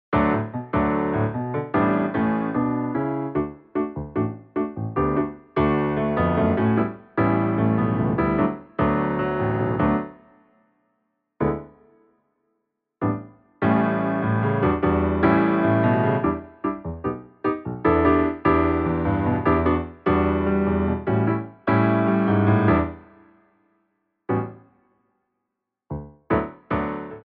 TACIT & STOP-TIME